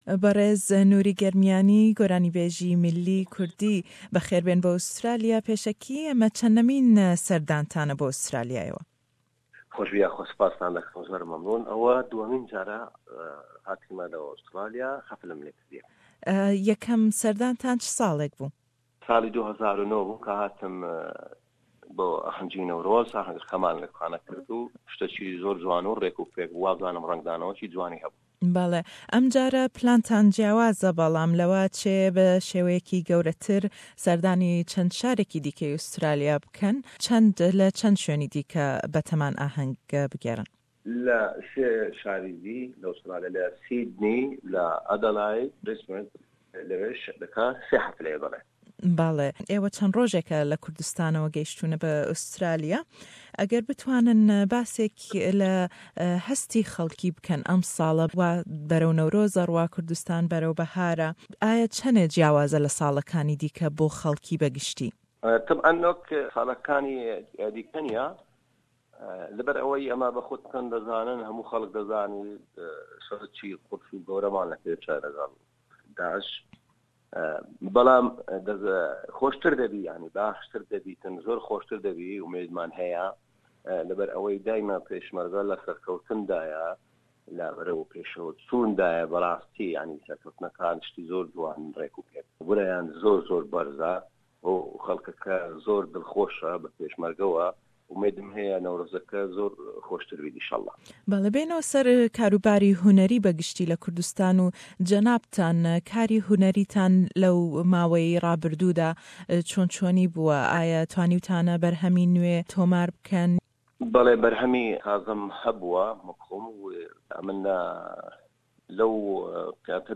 Lêdwanêk le gell hunermendî gorranî-bêj